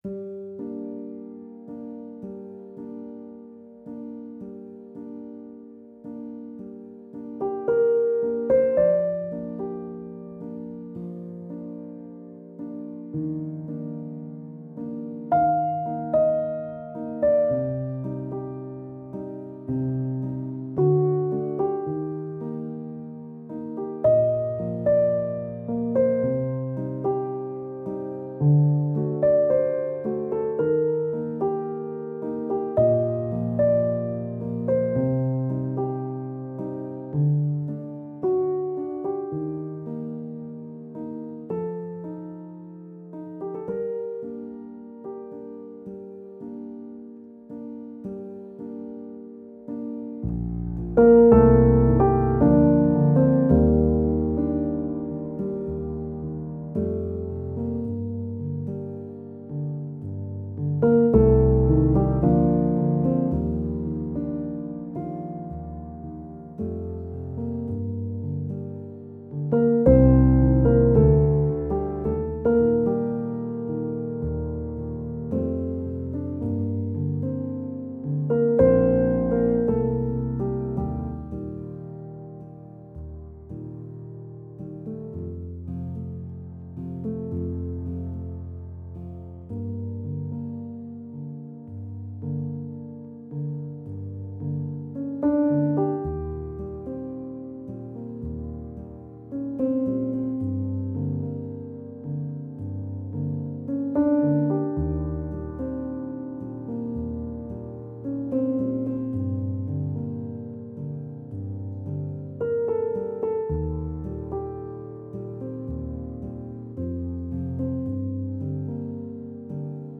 Fragile solo piano creating a nostalgic Nordic mood.